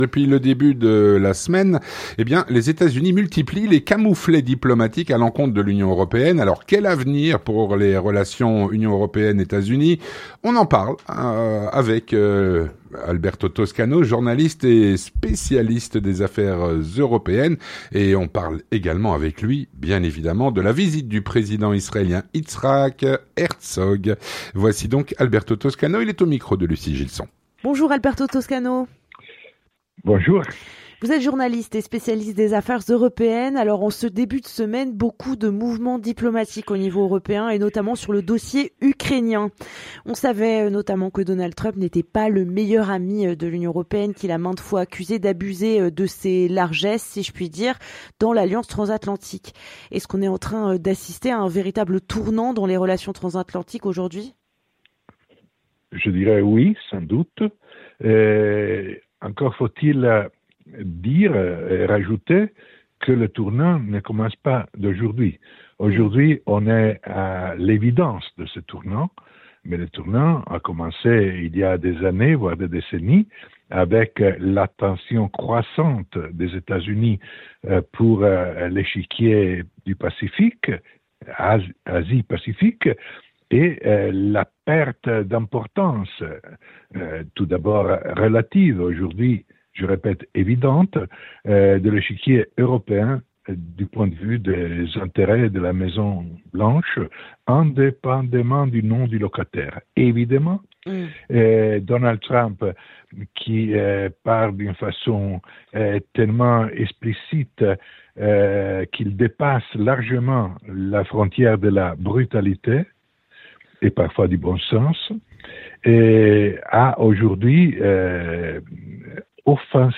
L'entretien du 18H - Les Etats-Unis multiplient les camouflets diplomatiques à l’encontre de l’Union européenne.